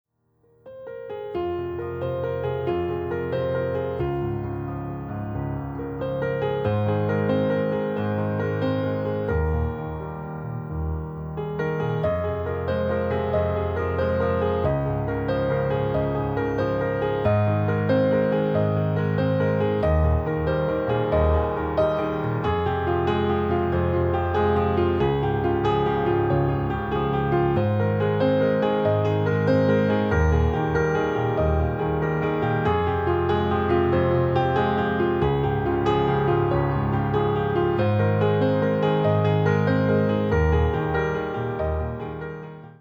• Качество: 320, Stereo
мелодичные
спокойные
без слов
инструментальные
классика
фортепиано
успокаивающие